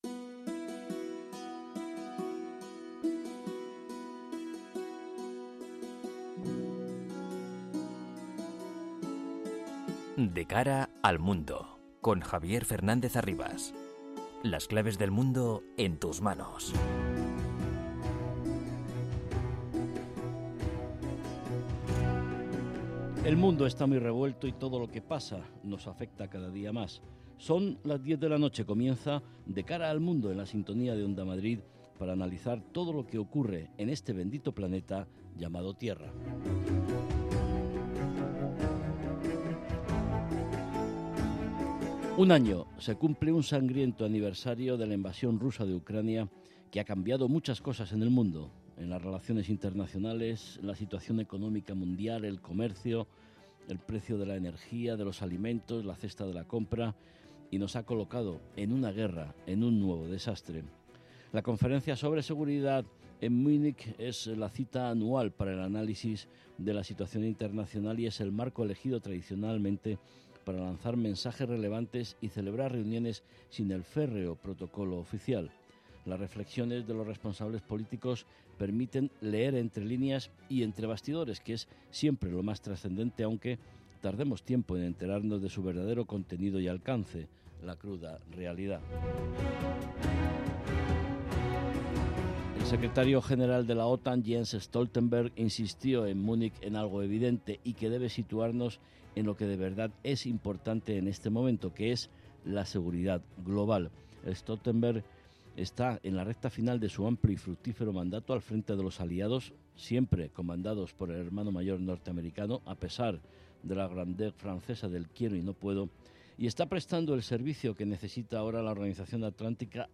con entrevistas a expertos y un panel completo de analistas